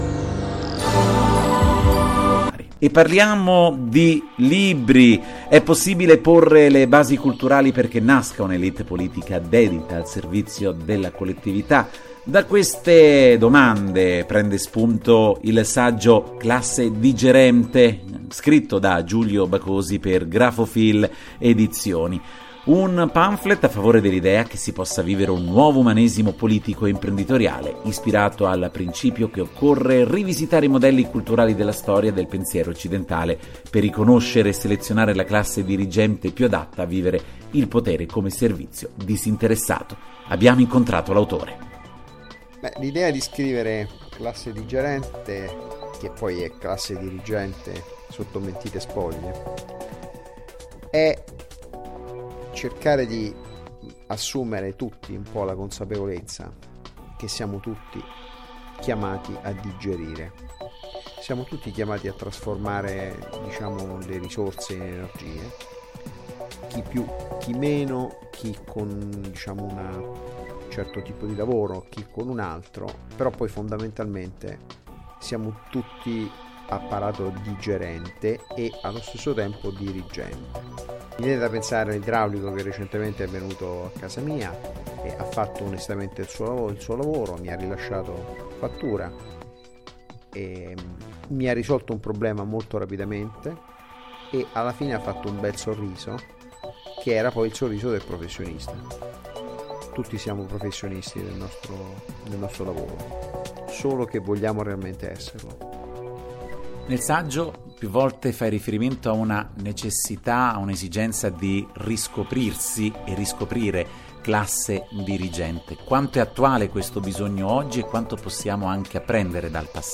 Intervista del 29 Giugno 2022